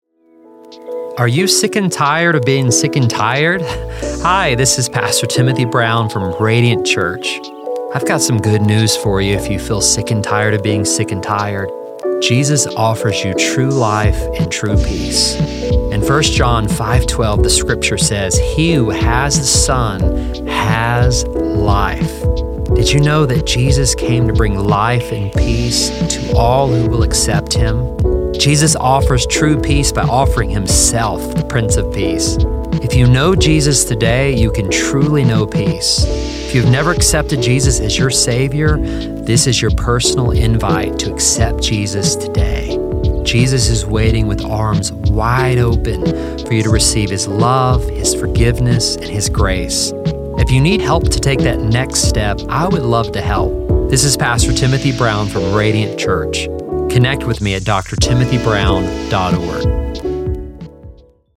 radio version